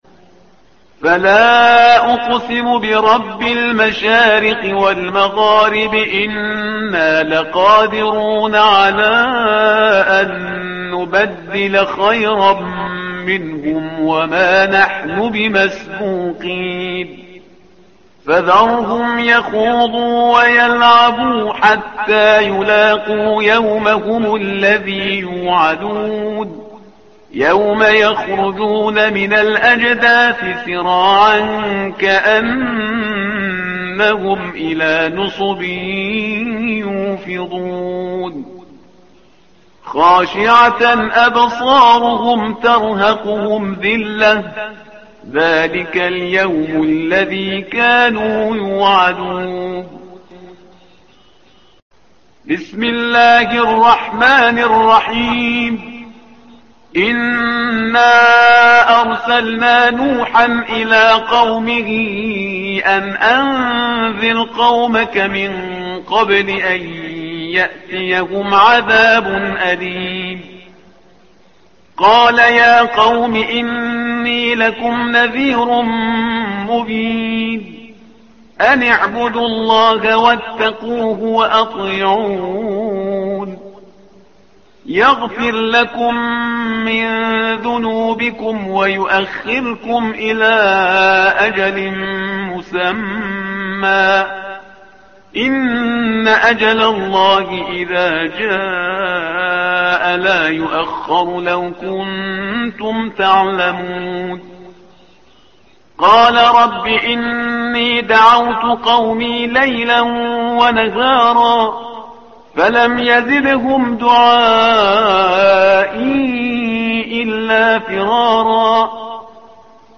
تحميل : الصفحة رقم 570 / القارئ شهريار برهيزكار / القرآن الكريم / موقع يا حسين